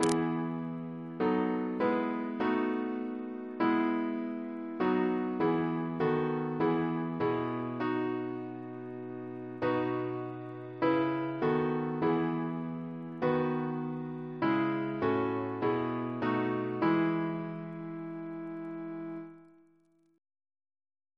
Double chant in E Composer: Roger R. Ross (1817-1899) Reference psalters: RSCM: 115